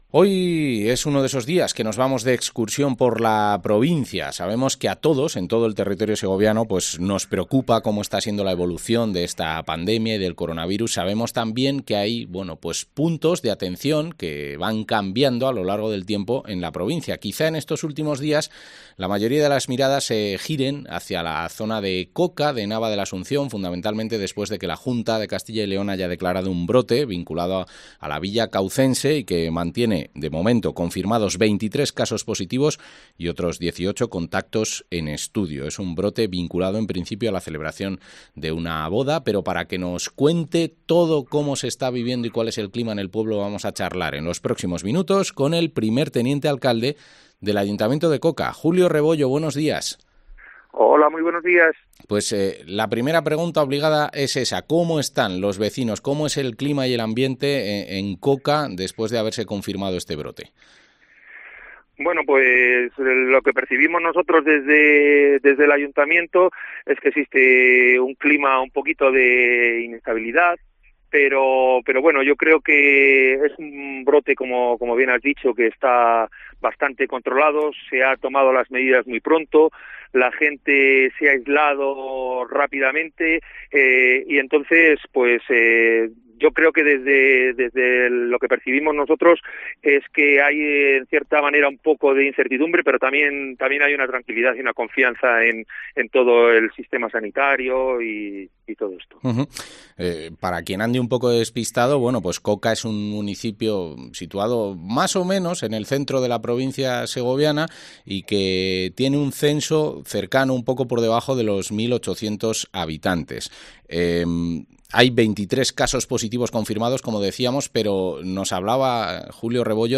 Entrevista al primer teniente alcalde de Coca, Julio Rebollo